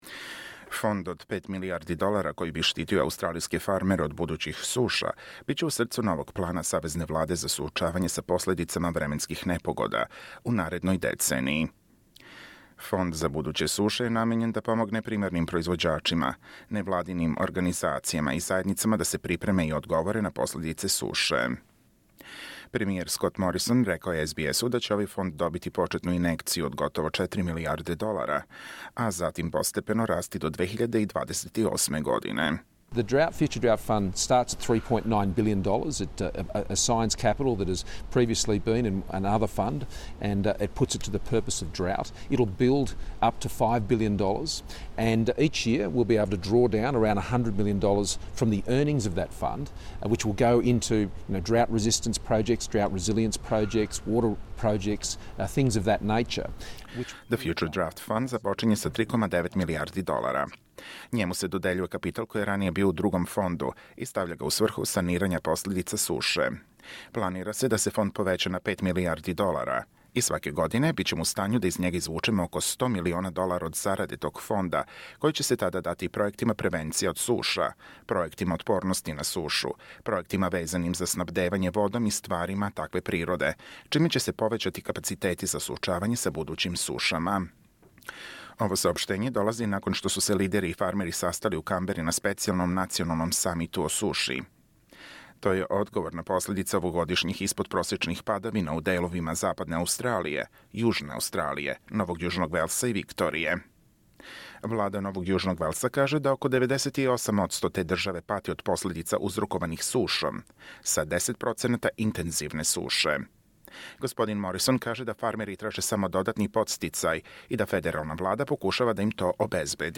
Prime Minister Scott Morrison in an interview with SBS Source: SBS